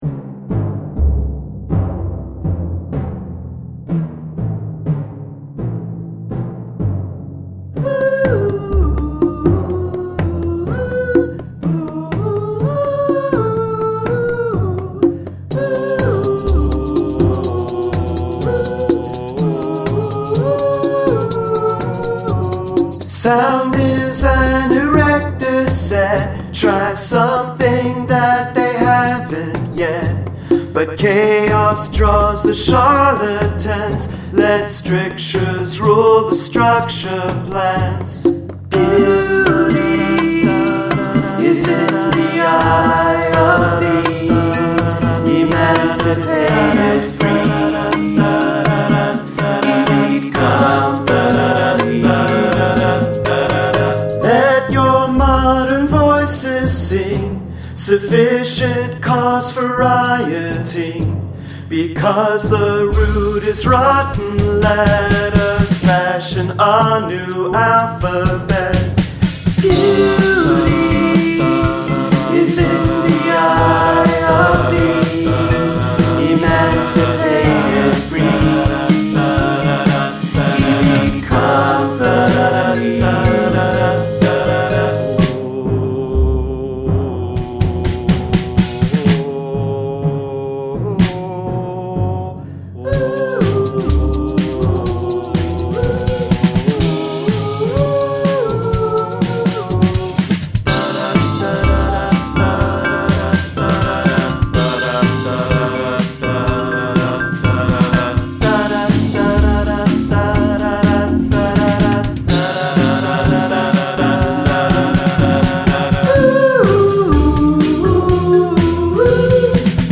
Only Percussion and Voice (no pianos allowed)